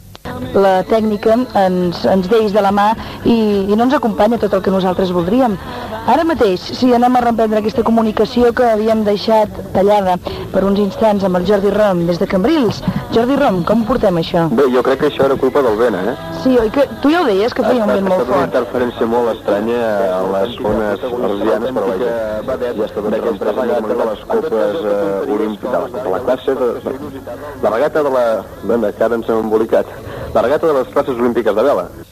Connexió, amb alguna interferrència, amb el Club de Vela de Cambrils